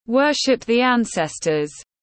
Thờ cúng tổ tiên tiếng anh gọi là worship the ancestors, phiên âm tiếng anh đọc là /ˈwɜː.ʃɪp ðiː ˈæn.ses.tər/
Worship the ancestors /ˈwɜː.ʃɪp ðiː ˈæn.ses.tər/
Worship-the-ancestors-.mp3